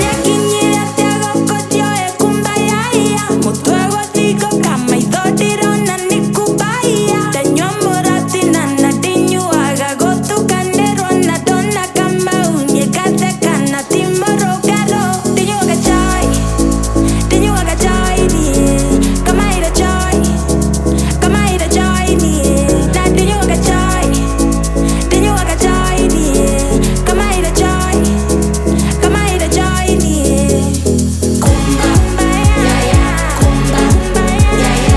House Dance Electronic
Жанр: Танцевальные / Хаус / Электроника